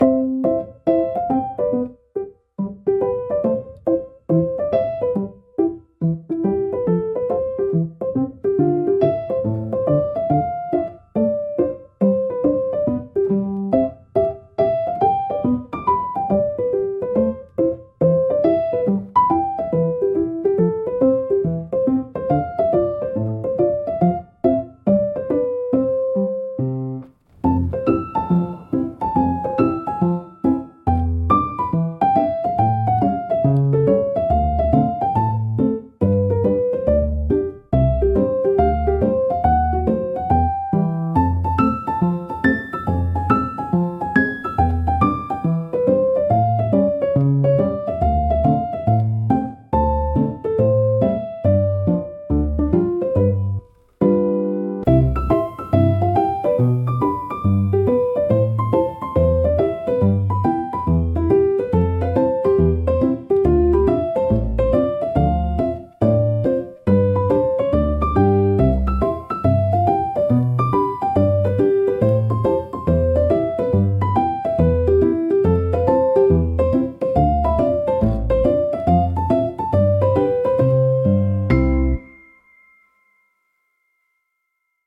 シンプルなメロディラインが心地よいリズムを生み、穏やかながらも前向きなムードを演出します。
ゆったりとした軽快なピアノソロが日常のさりげないシーンを優しく彩るジャンルです。
ピアノの柔らかなタッチが集中をサポートし、疲れを癒す効果を発揮します。